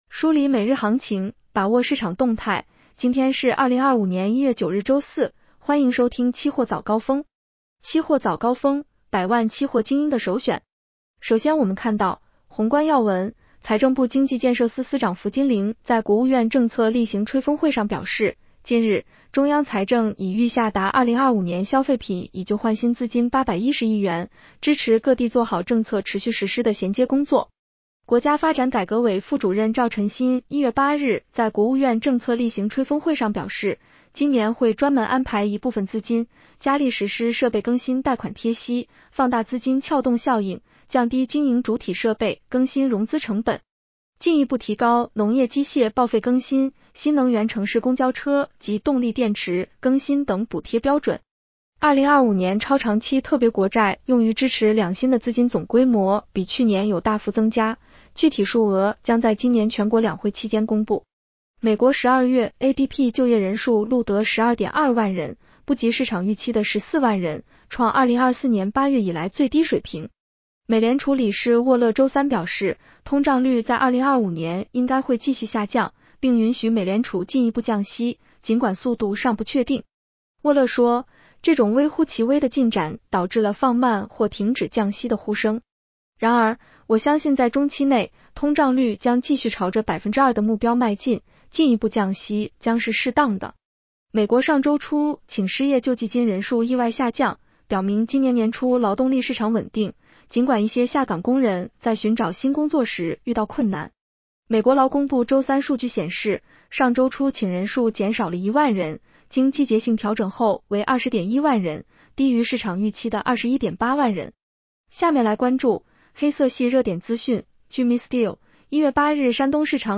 期货早高峰-音频版 女声普通话版 下载mp3 宏观要闻 1.